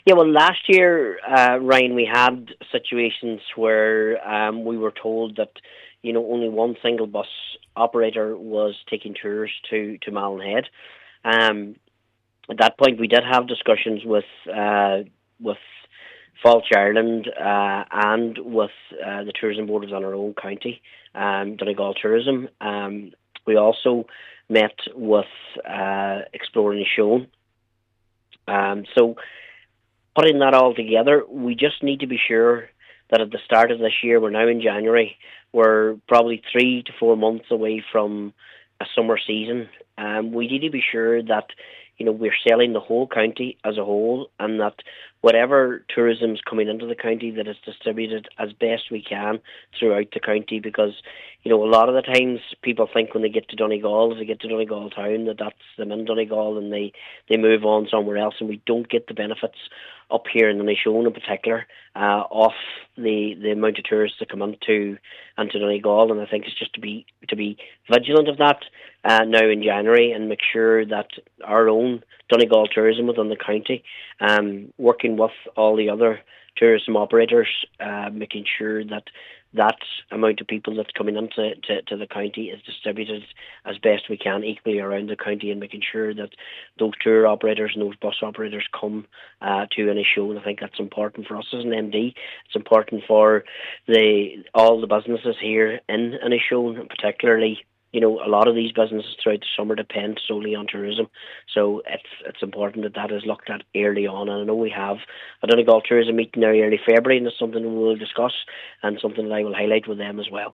MD Cathaoirleach, Martin McDermott said there needs to be early planning done ahead of this summer: